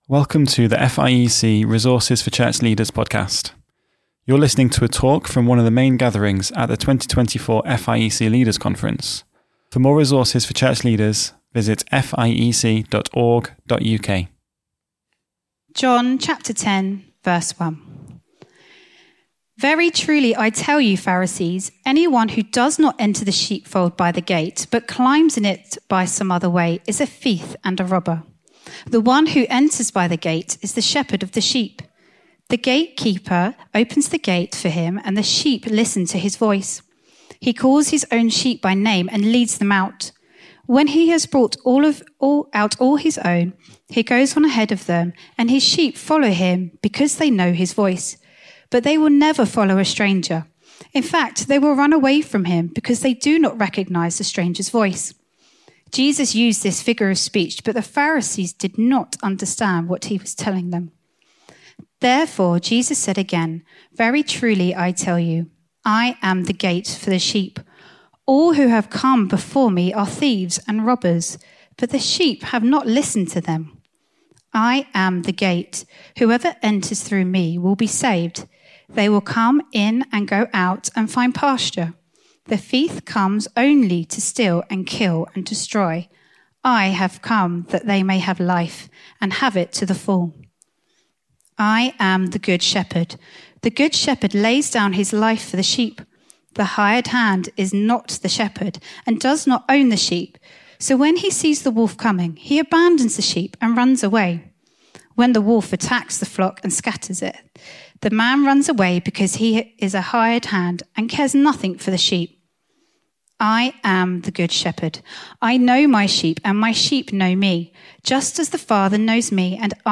I-Am-the-Good-Shepherd-FIEC-Leaders-Conference-2024.mp3